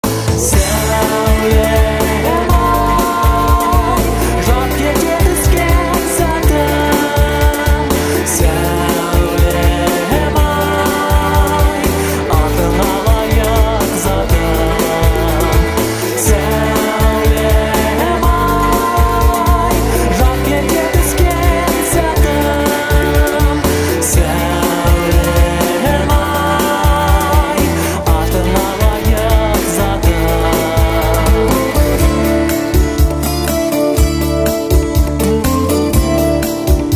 • Качество: 128, Stereo
гитара
романтичные